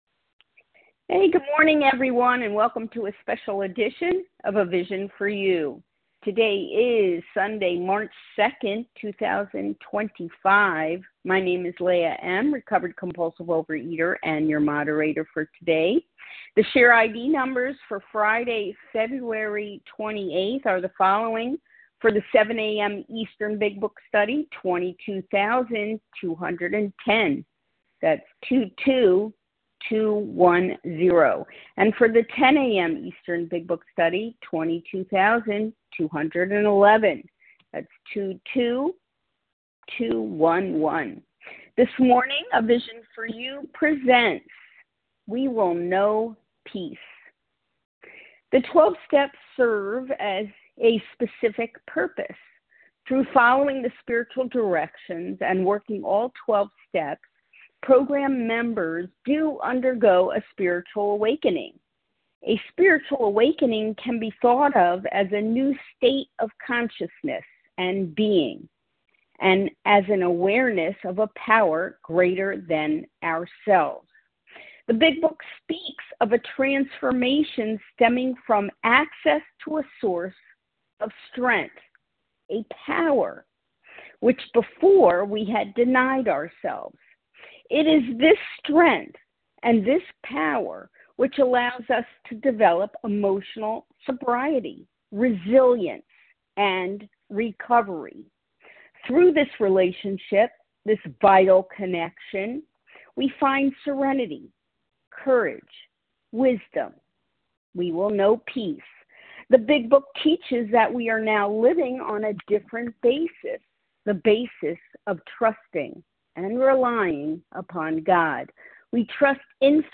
Overeaters Anonymous members share their experience, strength and hope on a number of different topics.